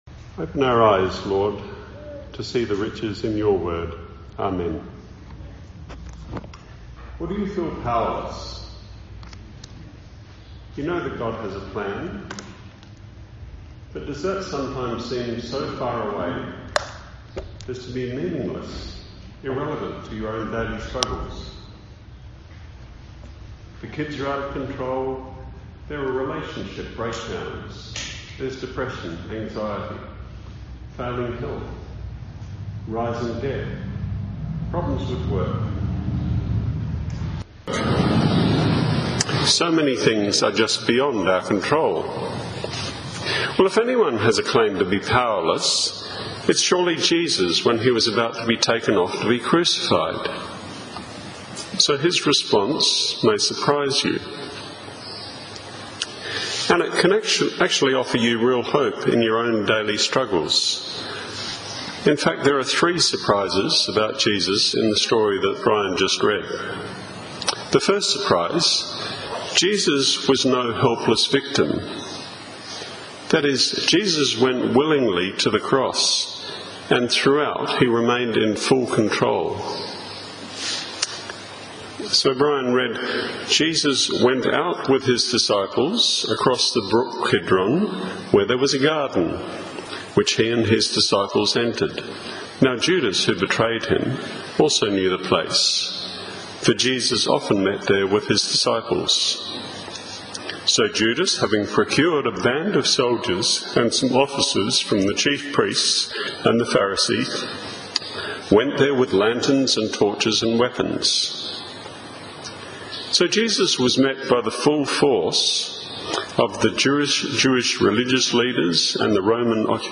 Download John 18.1-11 Theme: Jesus surprises. Sermon